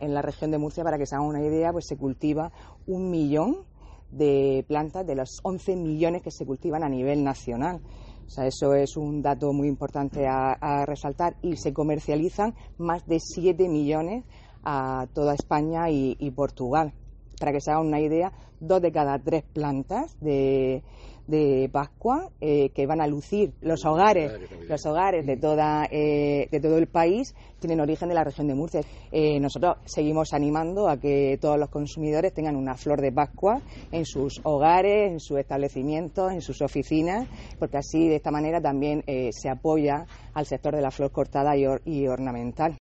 Sara Rubira, consejera de Agua, Agricultura, Ganadería y Pesca
Así lo expresó hoy la consejera de Agua, Agricultura, Ganadería y Pesca, Sara Rubira, durante su visita a los viveros de la empresa Barberet & Blanc, en Puerto Lumbreras, donde manifestó que “en todos los hogares de la Región de Murcia debe haber una flor de pascua, símbolo de nuestra tradición, que nos permite ofrecer una imagen acogedora en nuestras casas, negocios o plazas y jardines de los municipios”.